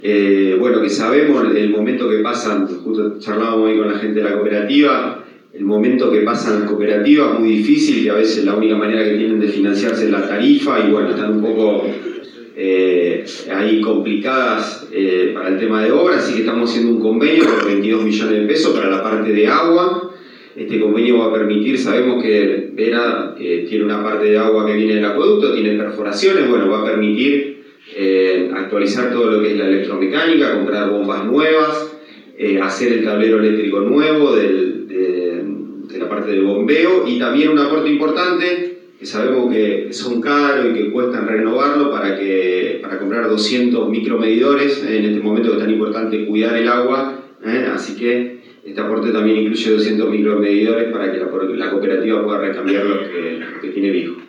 Leonel Marmirolli – Secretario de Agua y Saneamiento del gobierno provincial